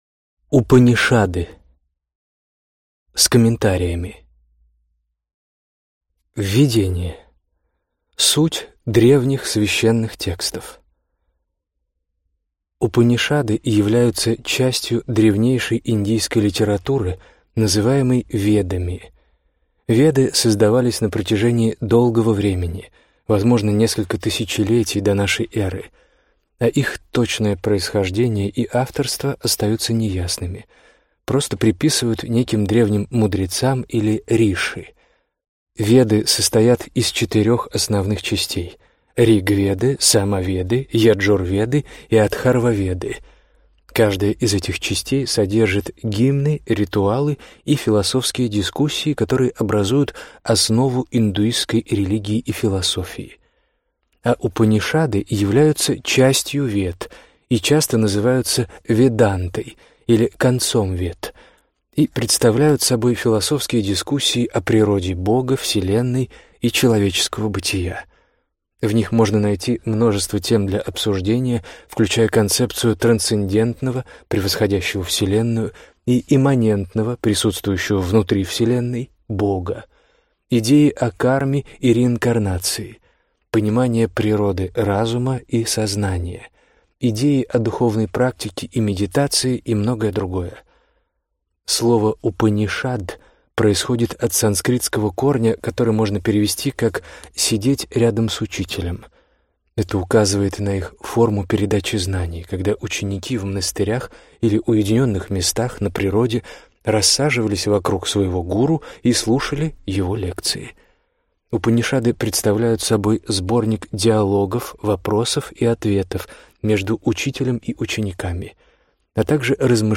Аудиокнига Упанишады: с комментариями | Библиотека аудиокниг
Прослушать и бесплатно скачать фрагмент аудиокниги